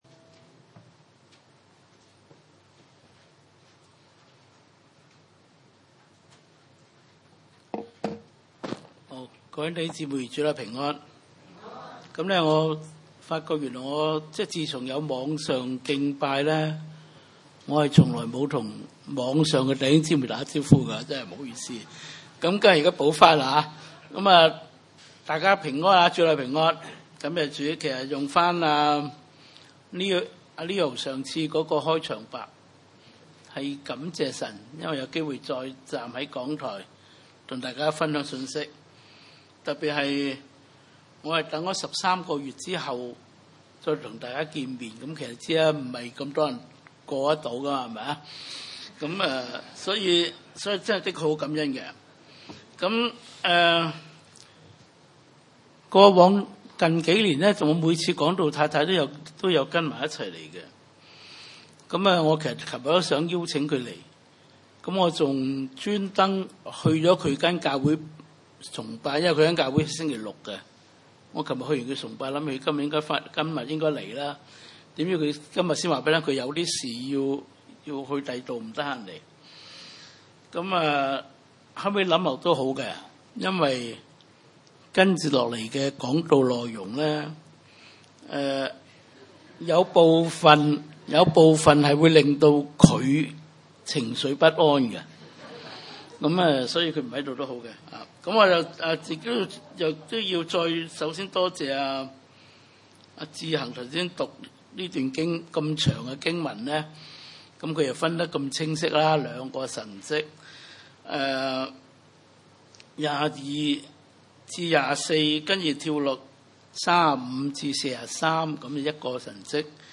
經文: 馬可福音5:22-43 崇拜類別: 主日午堂崇拜 22.有一個管會堂的人，名叫睚魯，來見耶穌，就俯伏在他腳前， 23.再三的求他，說：我的小女兒快要死了，求你去按手在他身上，使他痊癒，得以活了。